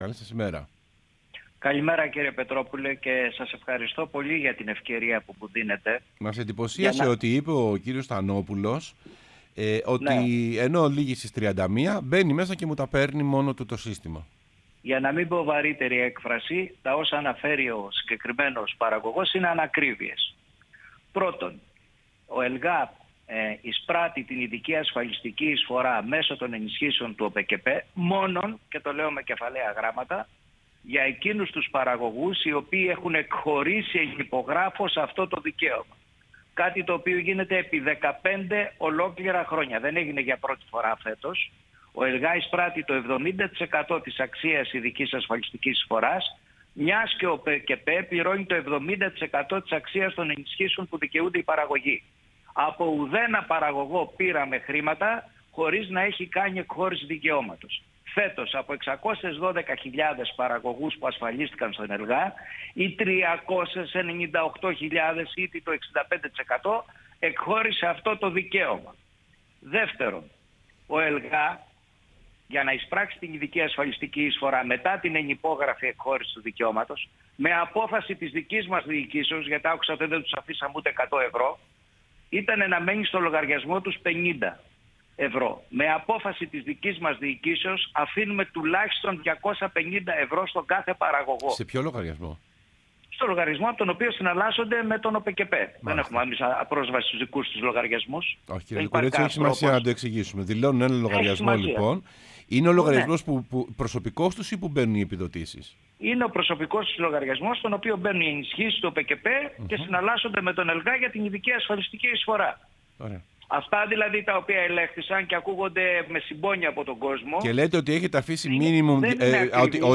ο Ανδρέας Λυκουρέντζος, πρόεδρος ΕΛΓΑ μίλησε στην εκπομπή Σεμνά και ταπεινά